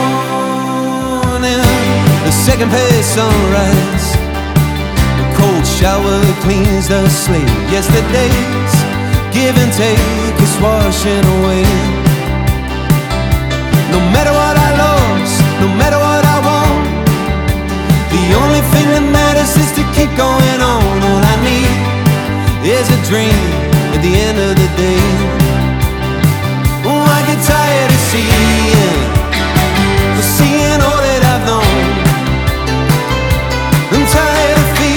2025-09-12 Жанр: Поп музыка Длительность